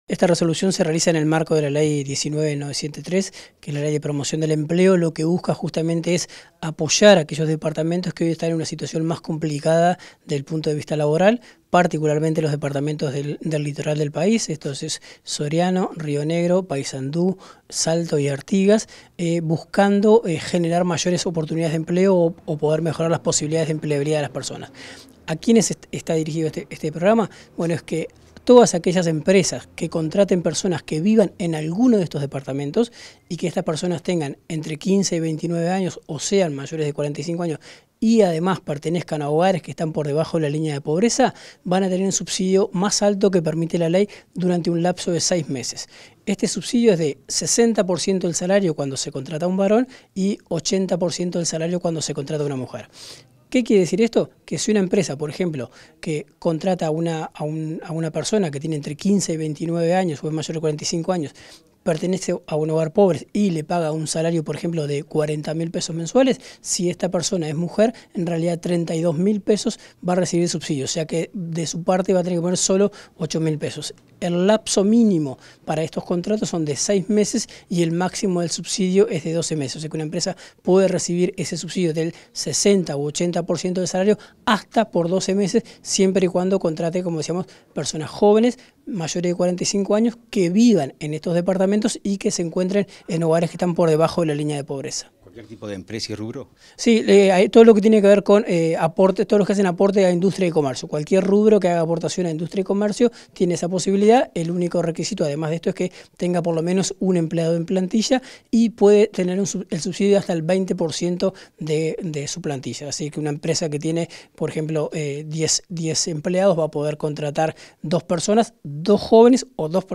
Entrevista al director nacional de Empleo, Daniel Pérez